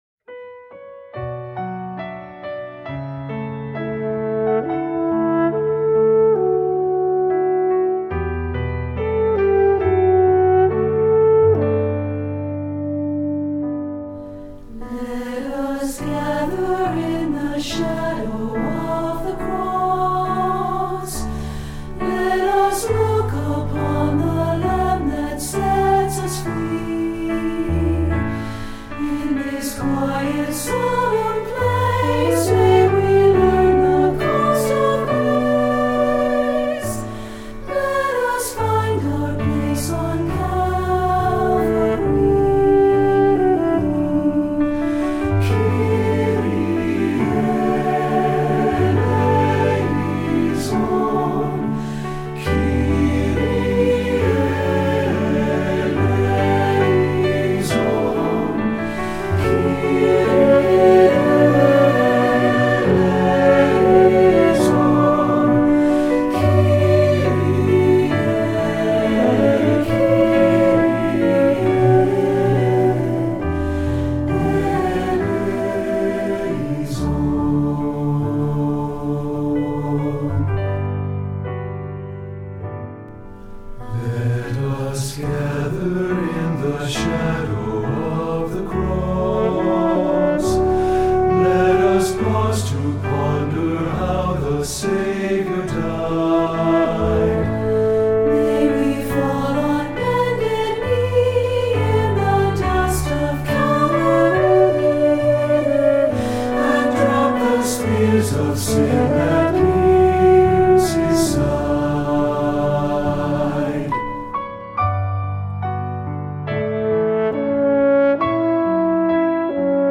Voicing: SATB, French Horn and Piano